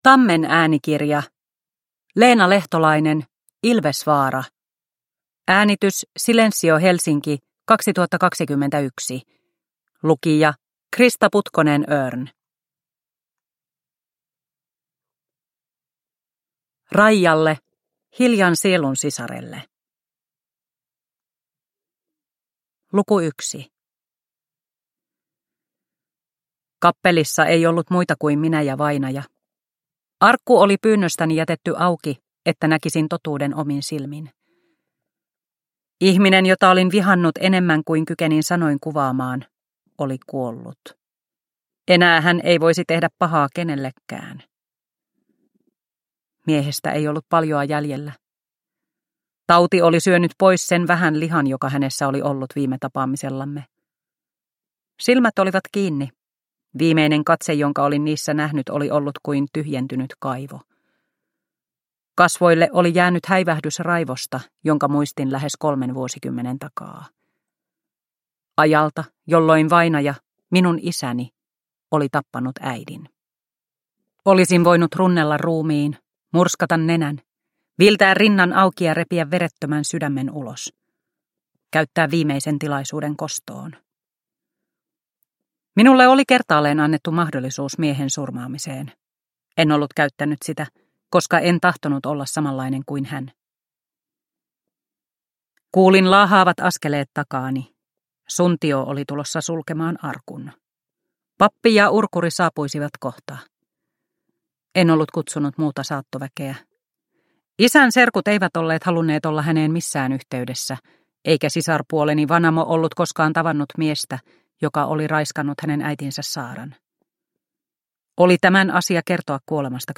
Ilvesvaara – Ljudbok – Laddas ner